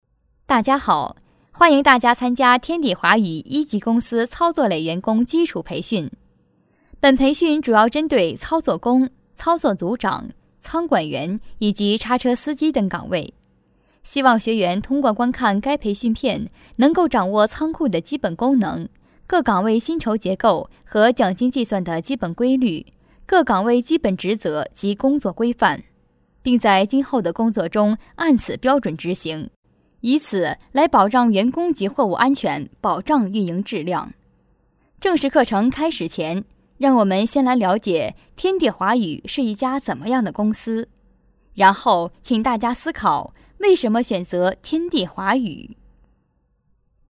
女声配音
课件女国52